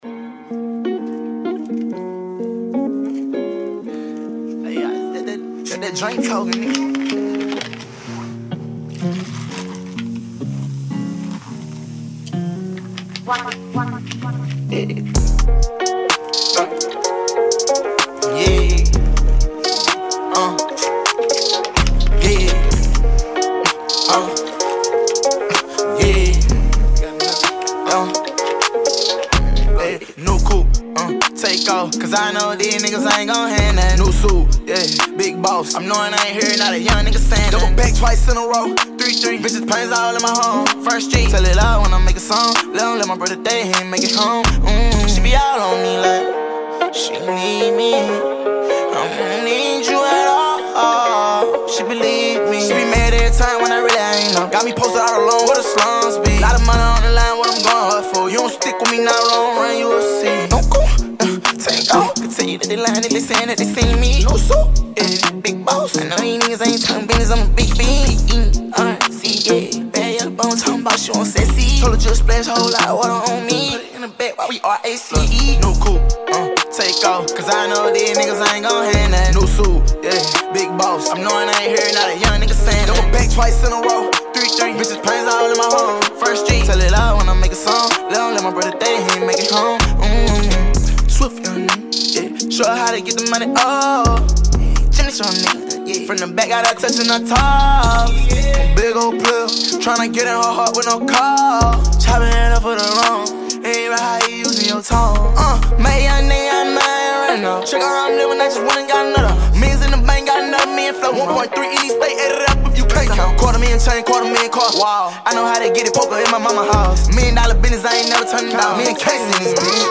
Lousiana rapper and Youtube’s most popular artiste